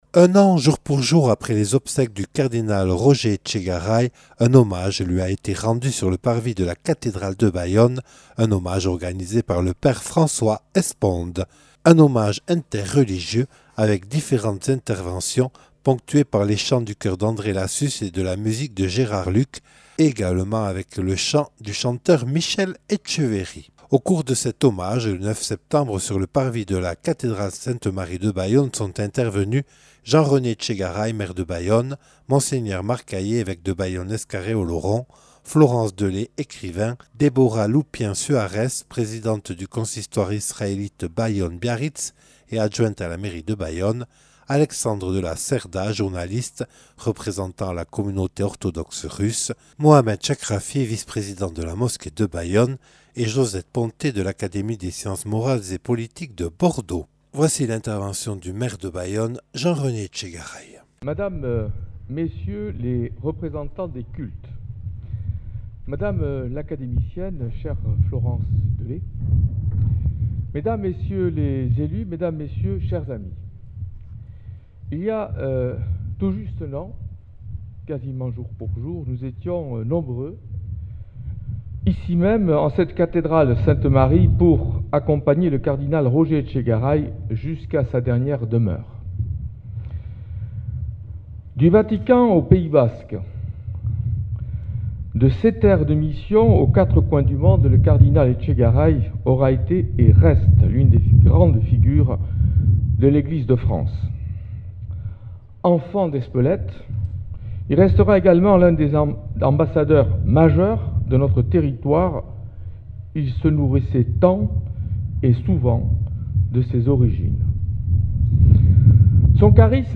Mercredi 9 septembre 2020, un an jour pour jour après les obsèques du cardinal Roger Etchegaray, un hommage lui a été rendu sur le parvis de la cathédrale de Bayonne.